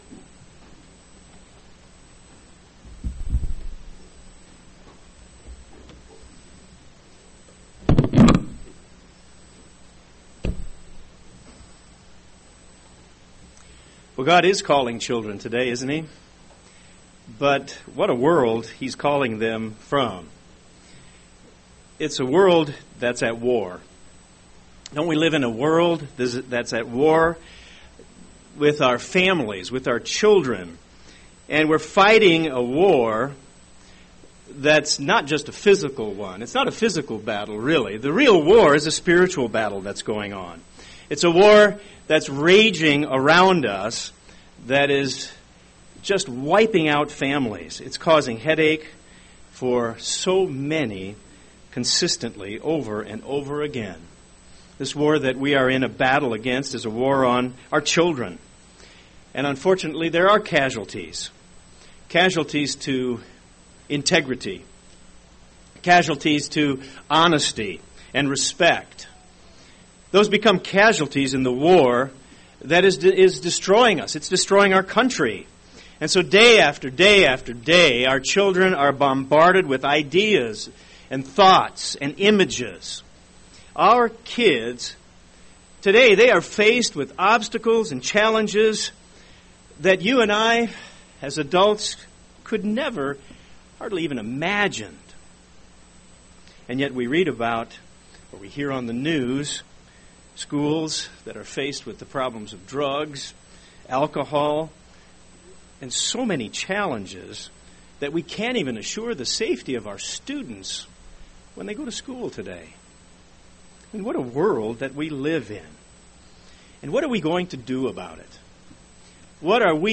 Its important for parents to teach their children God's truth. In this sermon, we will review what it says in Deuteronomy 6:4-9.